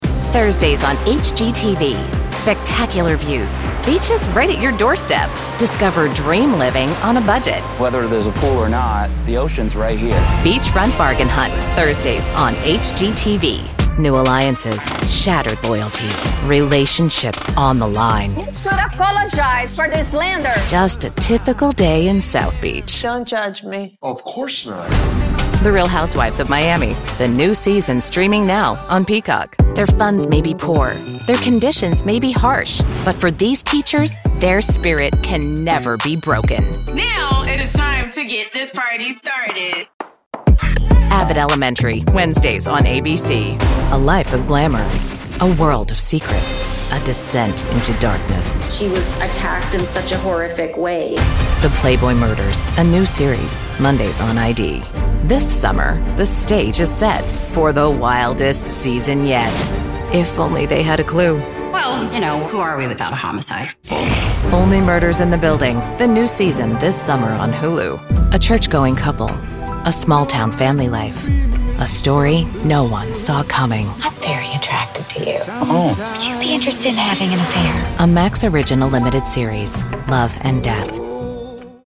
Female
Yng Adult (18-29), Adult (30-50)
Radio / TV Imaging
Promo - Television
Words that describe my voice are Conversational, Sophisticated, Real.
All our voice actors have professional broadcast quality recording studios.